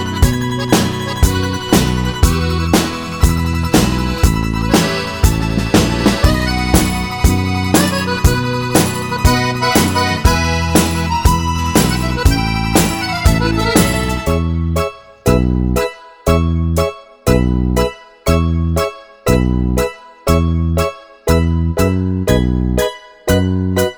no Backing Vocals Comedy/Novelty 3:12 Buy £1.50